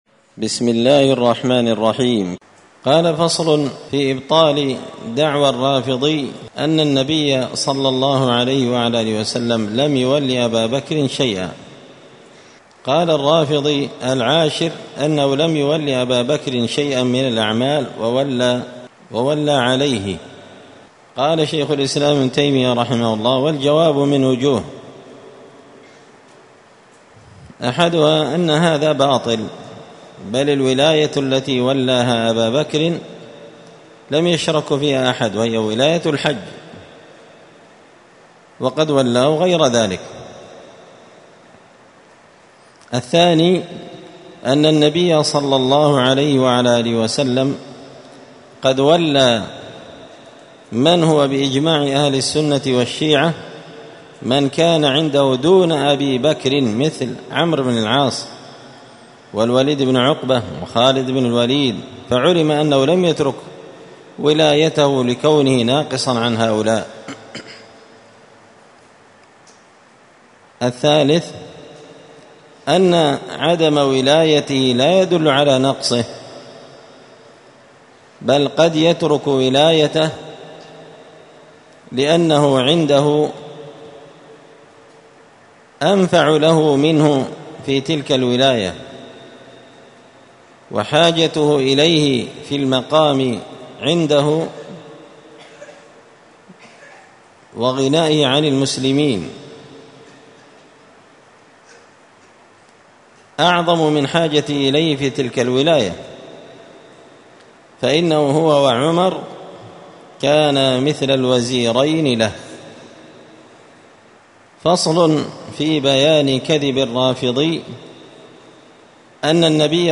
الأربعاء 12 ربيع الأول 1445 هــــ | الدروس، دروس الردود، مختصر منهاج السنة النبوية لشيخ الإسلام ابن تيمية | شارك بتعليقك | 59 المشاهدات
مسجد الفرقان قشن_المهرة_اليمن